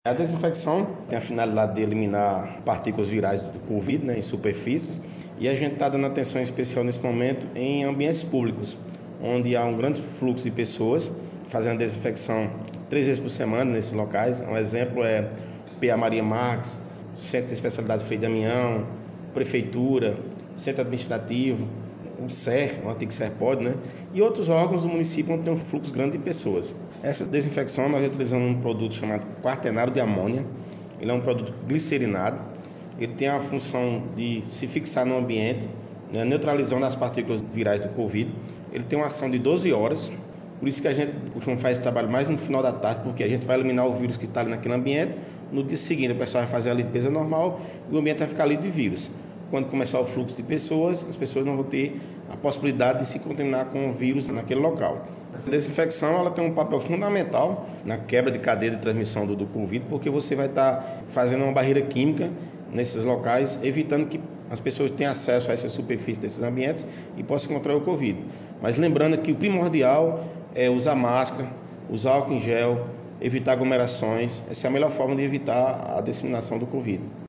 Fala